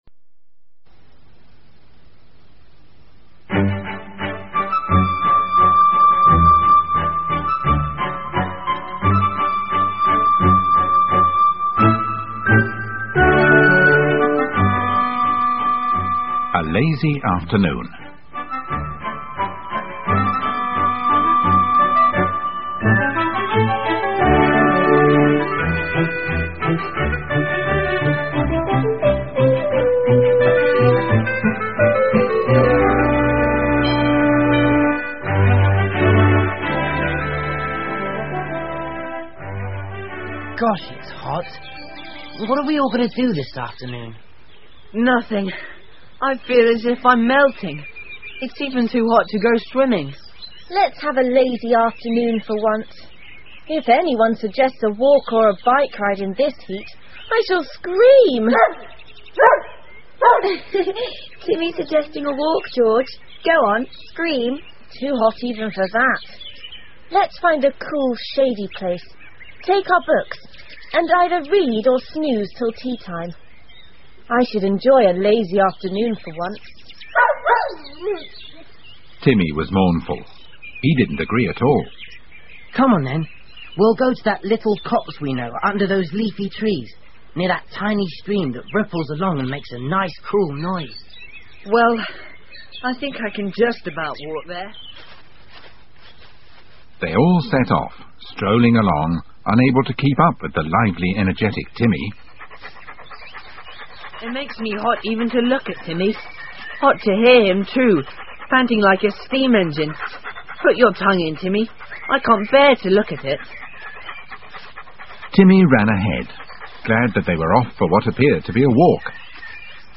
Famous Five Short Story Collection 儿童英文广播剧 21 听力文件下载—在线英语听力室